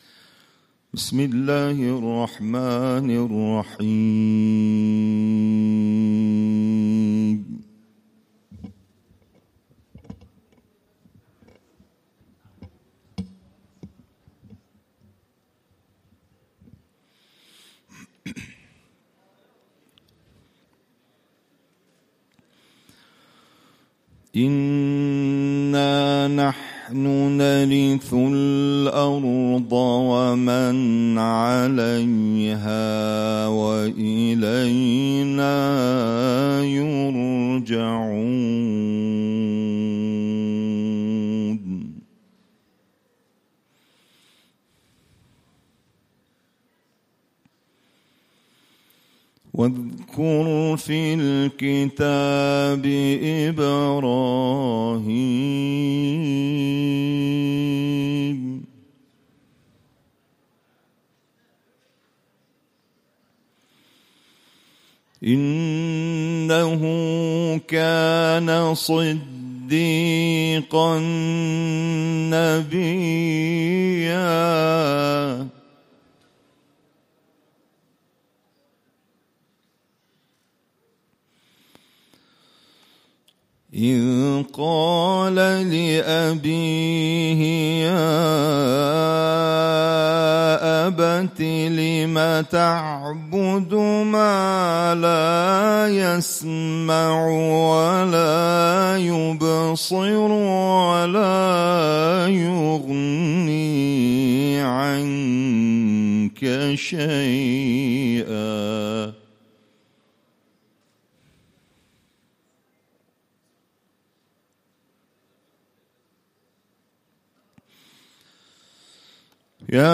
قاری بین‌المللی کشورمان در یکی دیگر از محافل قرآنی دانشگاه امیرکبیر که به صورت هفتگی برگزار می‌شود، به تلاوت آیاتی از کلام‌الله مجید پرداخت.